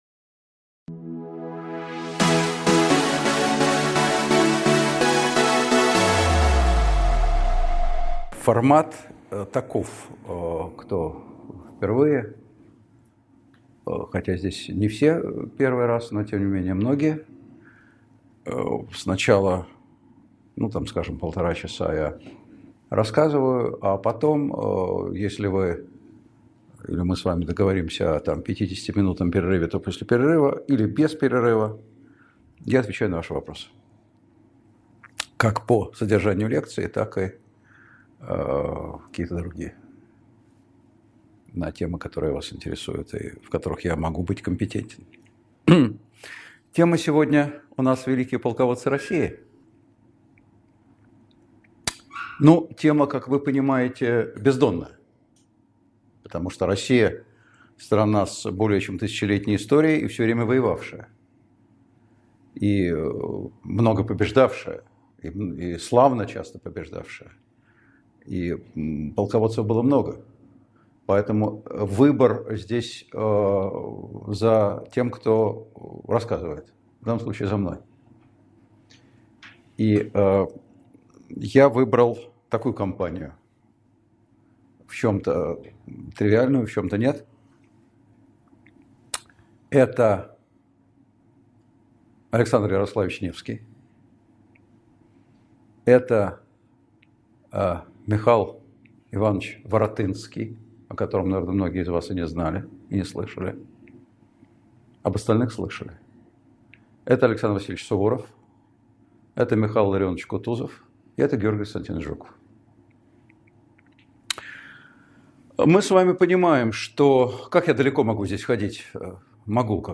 Aудиокнига Великие полководцы России Автор Николай Сванидзе Читает аудиокнигу Николай Сванидзе.